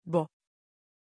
Pronunciation of Bo
pronunciation-bo-tr.mp3